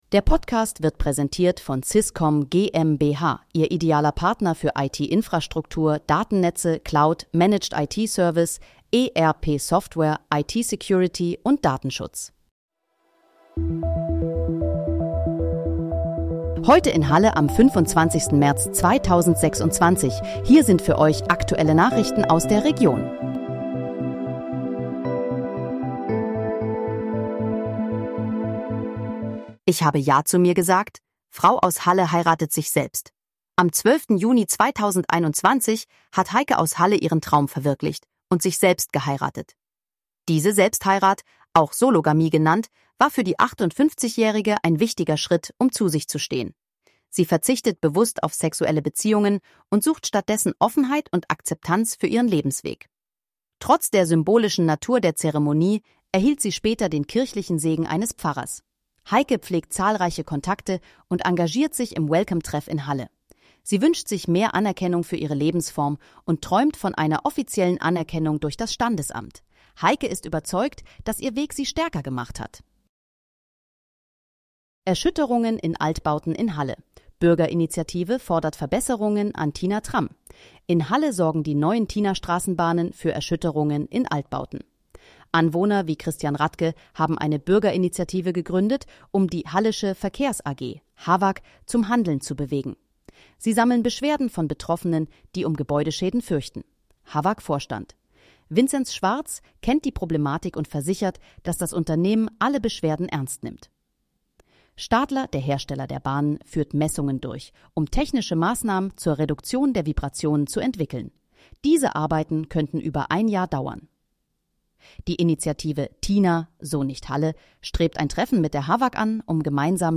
Heute in, Halle: Aktuelle Nachrichten vom 25.03.2026, erstellt mit KI-Unterstützung
Nachrichten